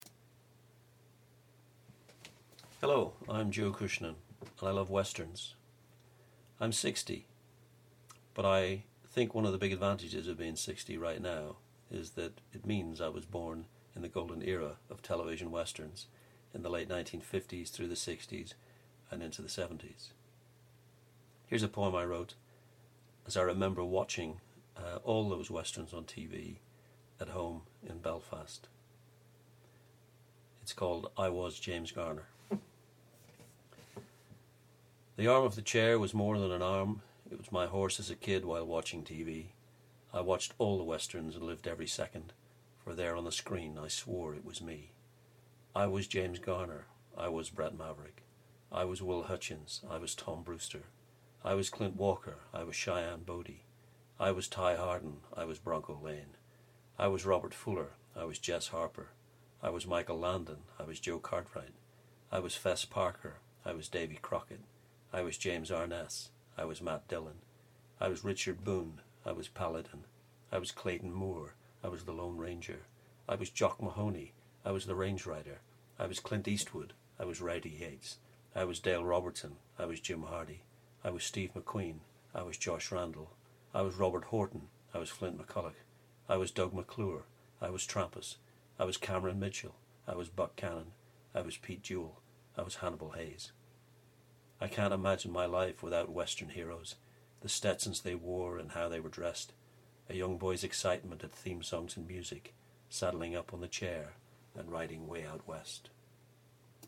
A poem about my love of the TV westerns of the late 1950s and through the 1960s....